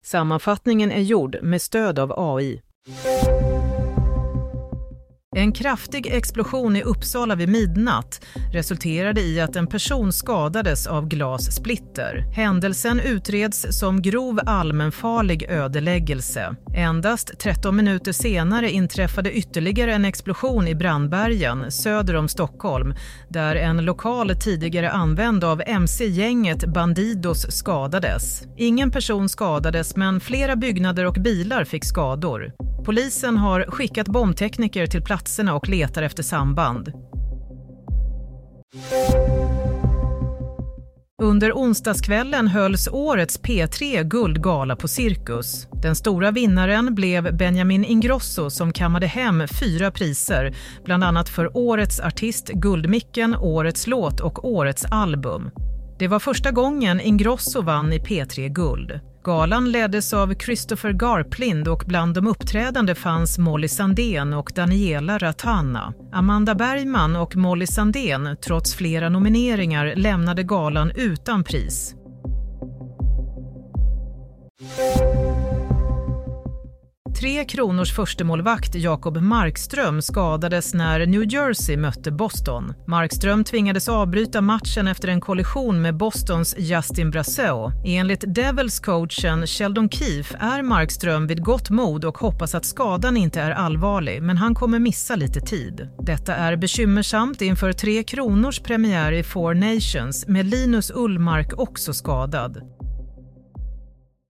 Nyhetssammanfattning - 23 januari 07.30